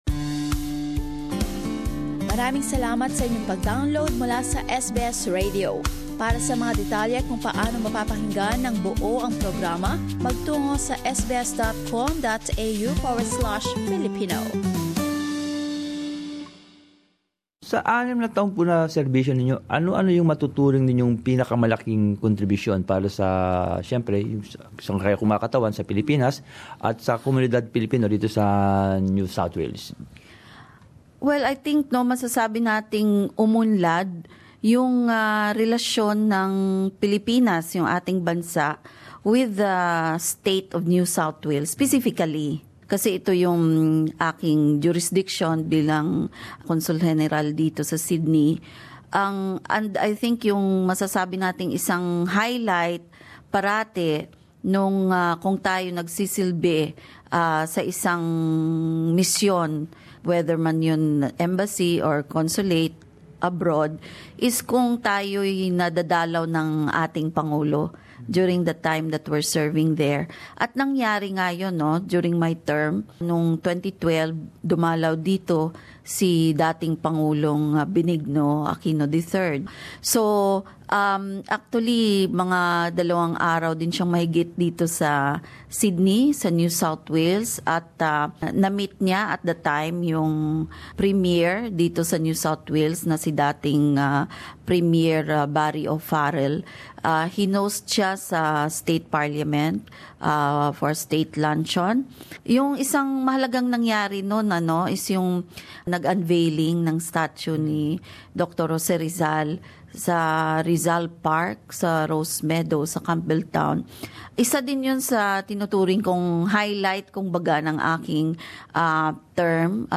Philippine Consul General for NSW Anne Jalando-on Louis being interviewed Source: SBS FIlipino